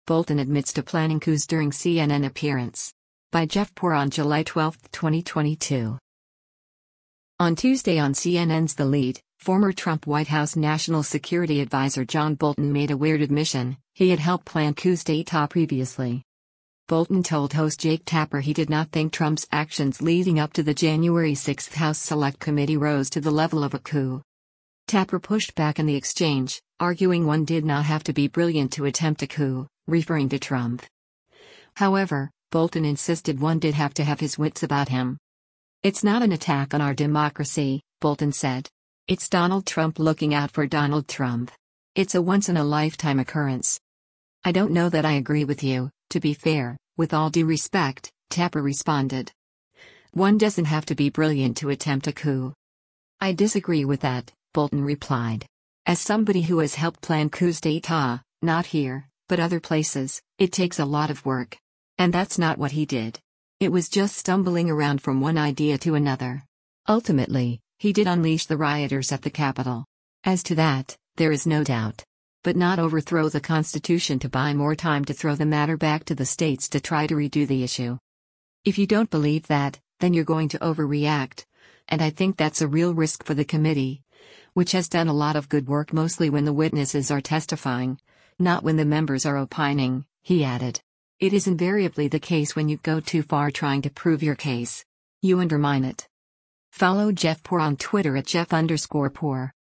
Bolton told host Jake Tapper he did not think Trump’s actions leading up to the January 6 House Select Committee rose to the level of a coup.